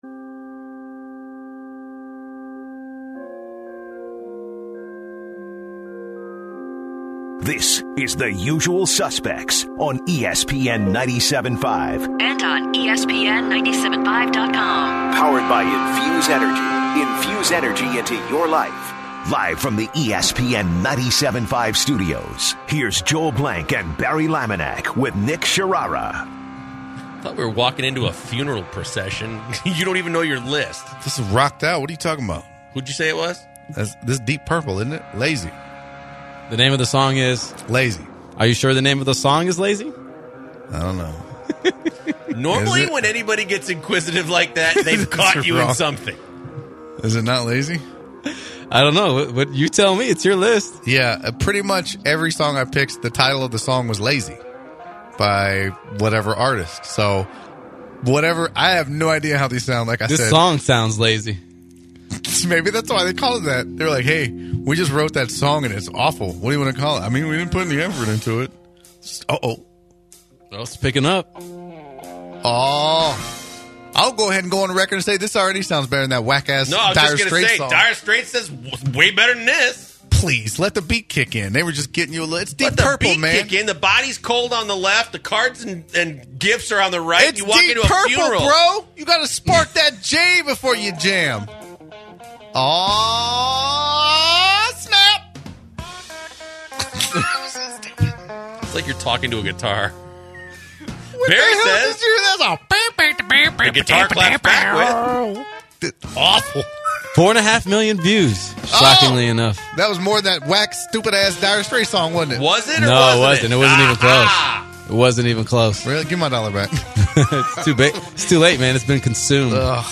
The guys start the third hour talking about the Rockets and Chris Broussard’s opinion. They take callers and the guys get into it with a caller since the caller thought their opinion was wrong and they don’t have the correct credentials to give that opinion. The start talking about what Ayesha Curry said in an interview which brought lots of callers with their opinions.